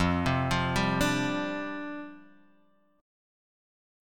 F 6th Suspended 2nd